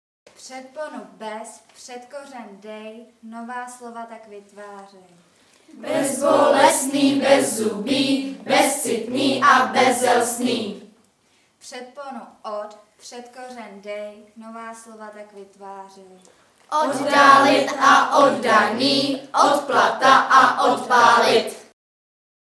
Tvoření slov příbuzných pomocí předpon_říkadlo_ČESKÝ JAZYK V RÝMECH